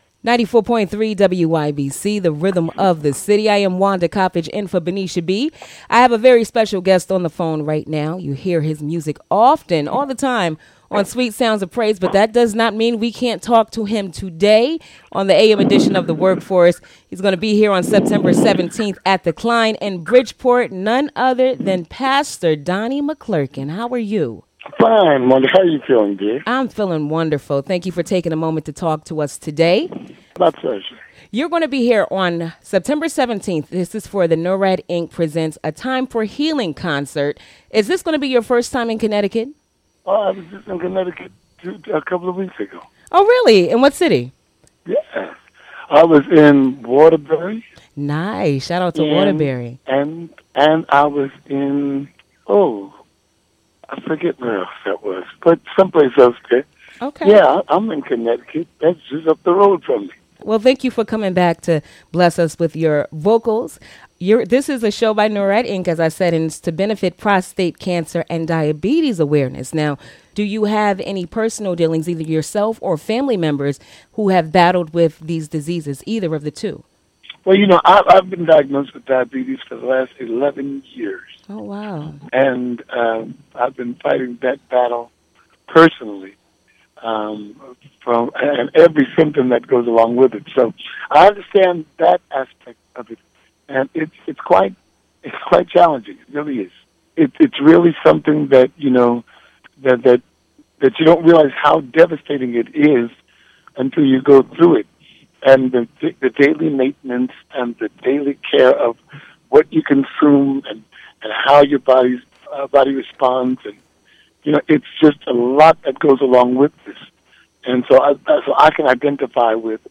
Pastor Donnie McClurkin Interview w